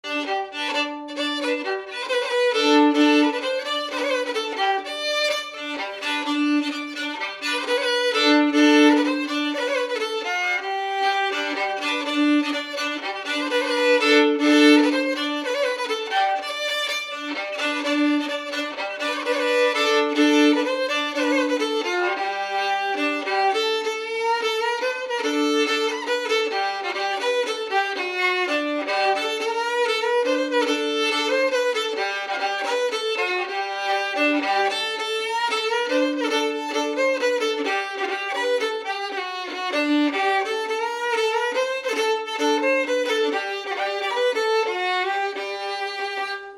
Mazurka
Résumé instrumental
danse : mazurka
Pièce musicale inédite